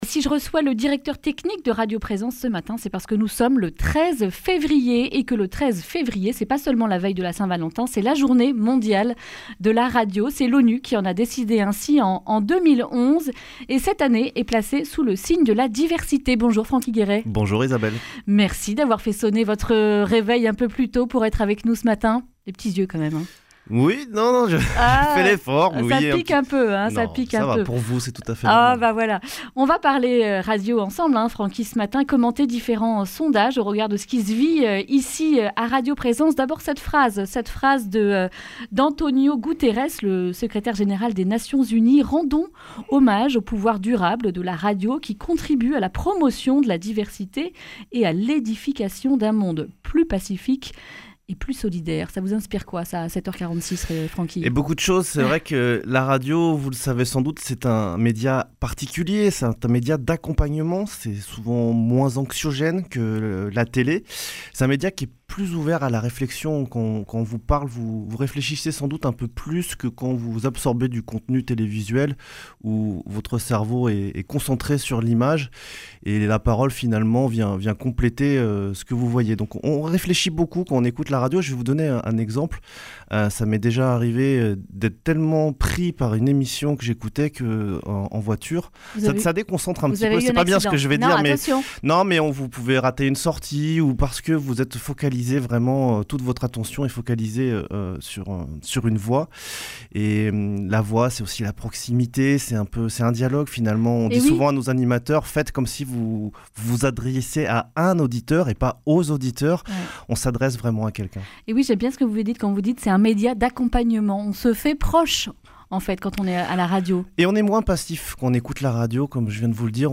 jeudi 13 février 2020 Le grand entretien Durée 10 min
Une émission présentée par